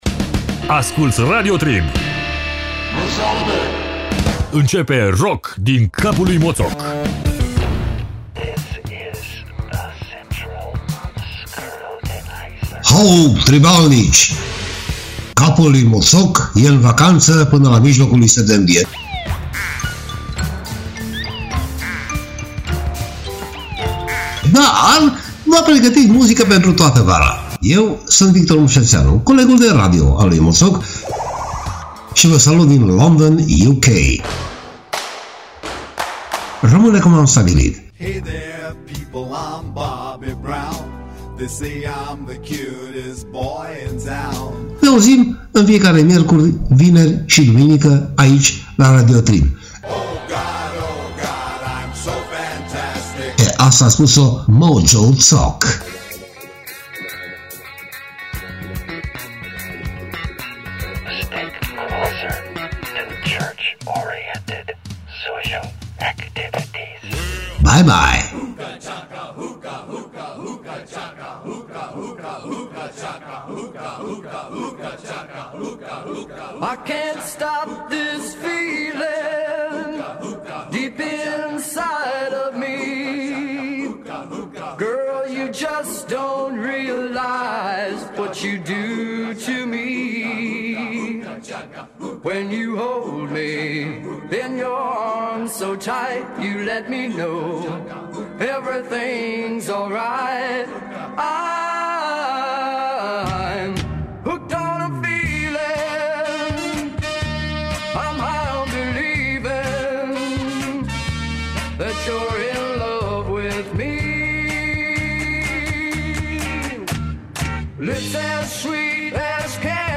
Elder e a doua gasca ce se aude in emisiunea de saptamana asta.